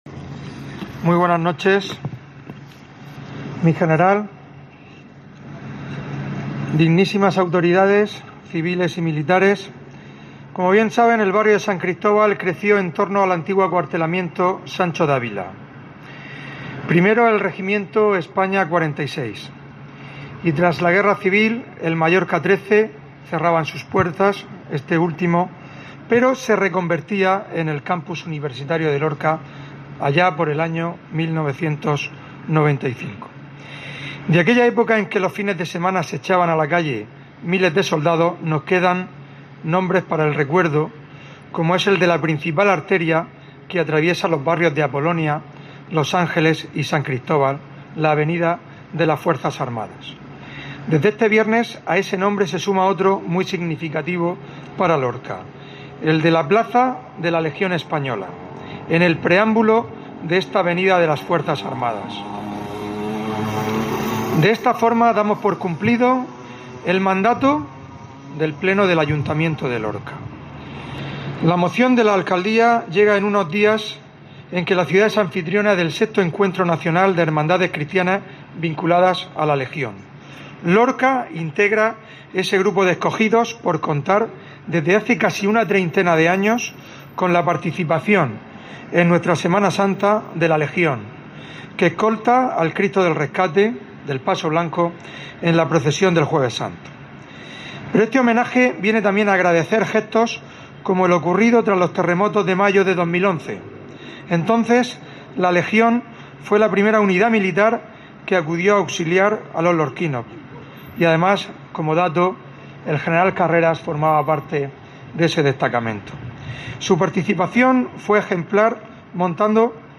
Fulgencio Gil, alcalde de Lorca